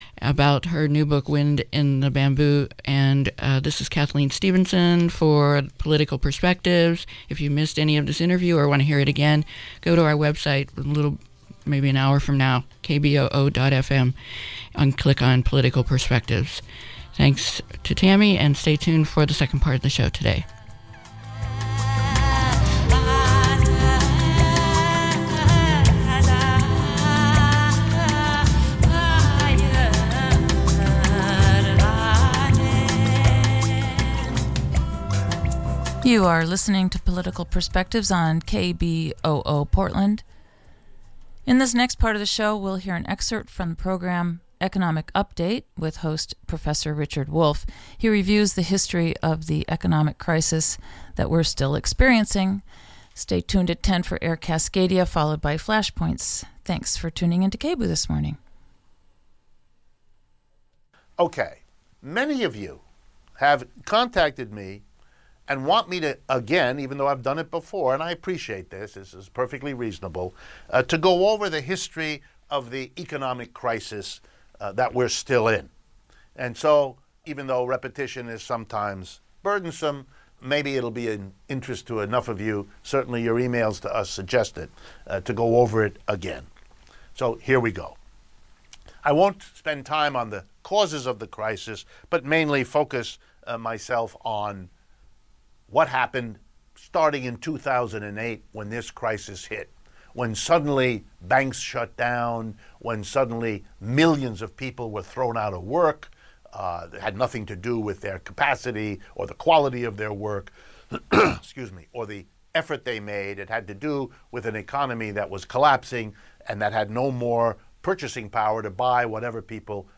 We present a panel discussion